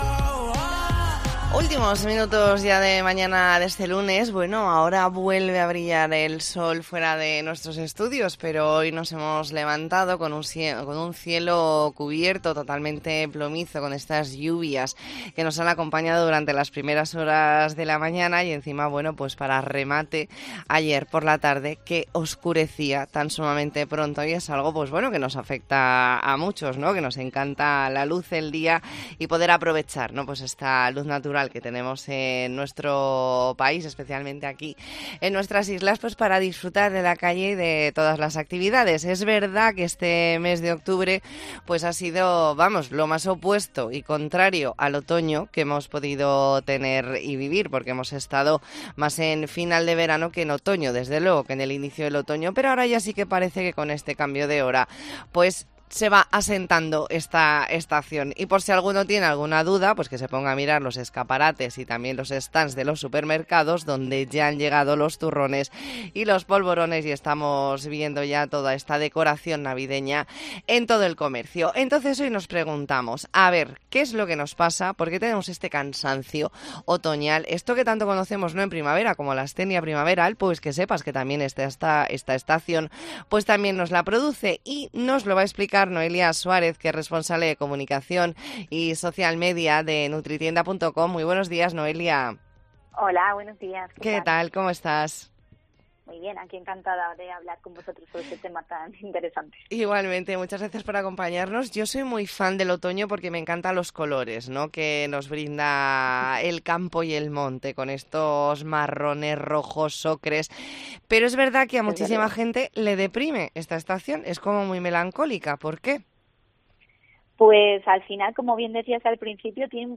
Entrevista en La Mañana en COPE Más Mallorca, lunes 30 de octubre de 2023.